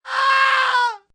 Screaming Death 2